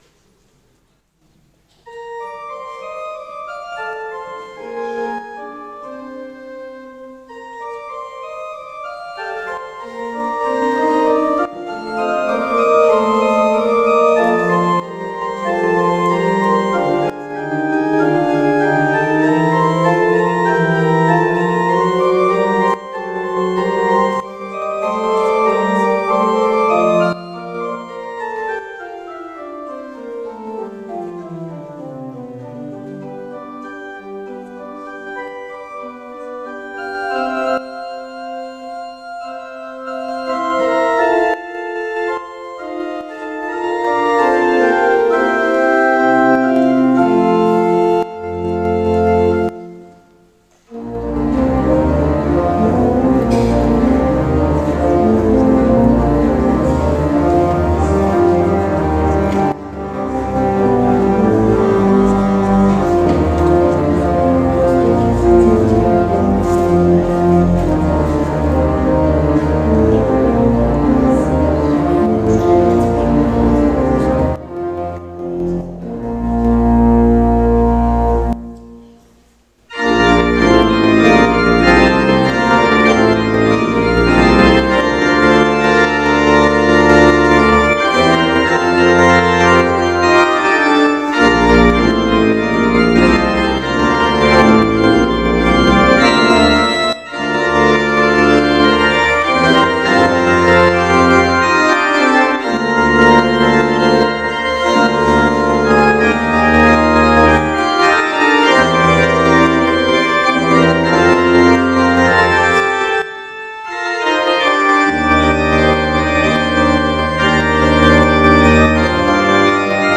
Gottesdienst vom 21.12.2025 als Audio-Podcast Liebe Gemeinde, herzliche Einladung zum Gottesdienst vom 21. Dezember 2025 in der Martinskirche als Audio-Podcast.